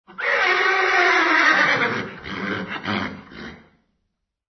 Descarga de Sonidos mp3 Gratis: relincho 1.